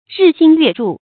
日新月著 注音： ㄖㄧˋ ㄒㄧㄣ ㄩㄝˋ ㄓㄨˋ 讀音讀法： 意思解釋： 日日更新，月月顯著。形容蓬勃發展。